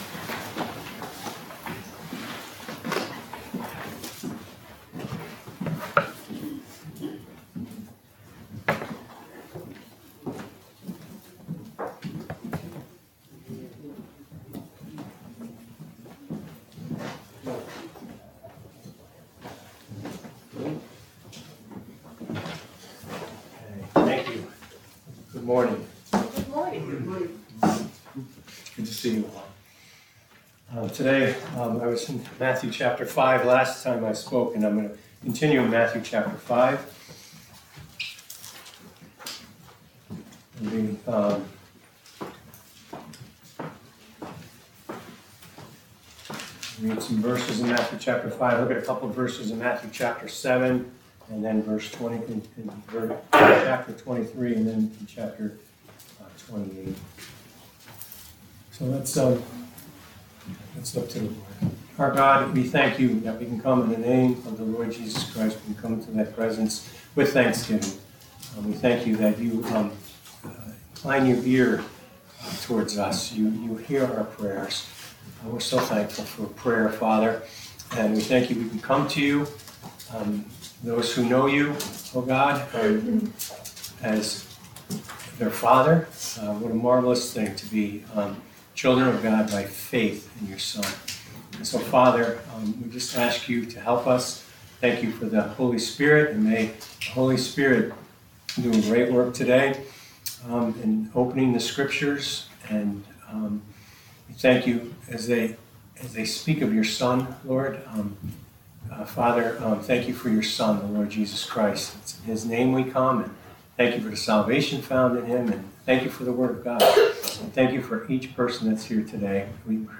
Light of the World Passage: Matthew 5:13 Service Type: Sunday Afternoon « 01.29.23